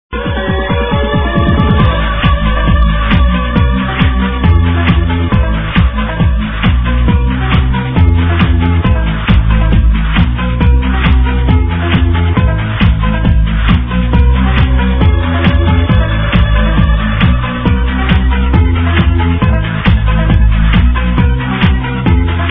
awesome trance track